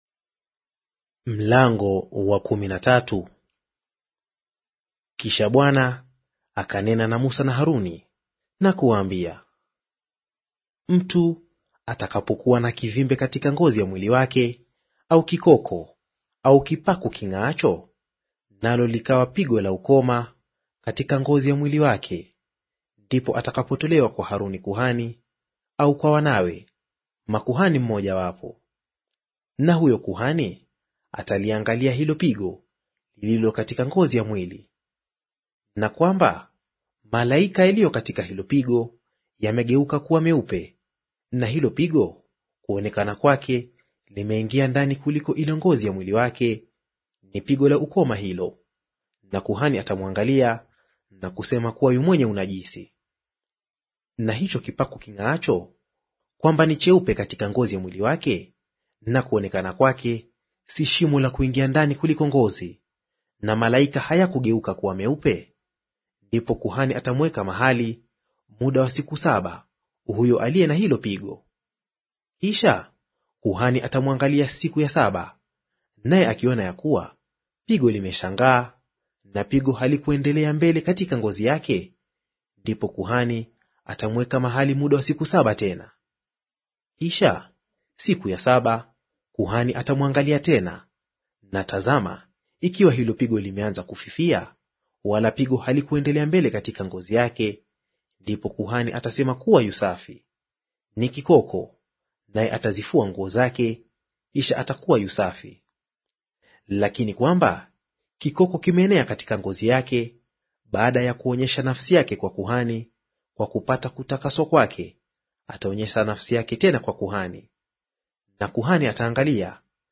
Audio reading of Mambo ya Walawi Chapter 13 in Swahili